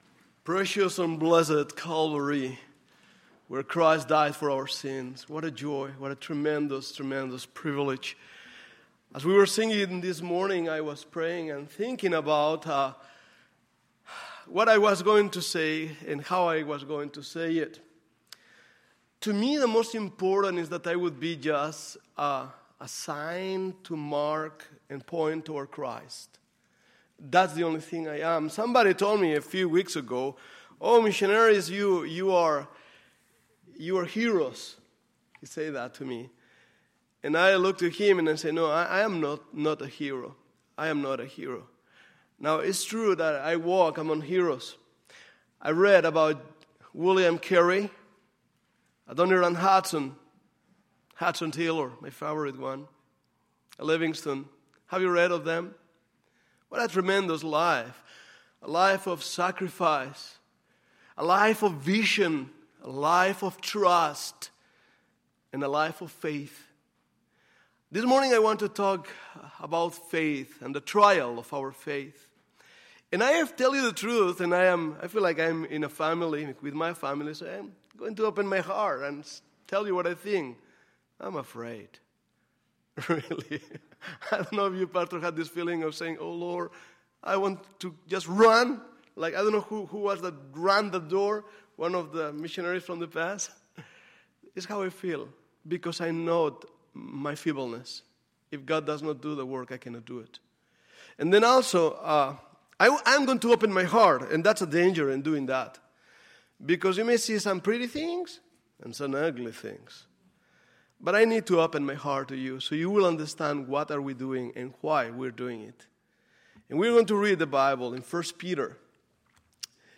Sunday, August 6, 2017 – Sunday Morning Service
Sermons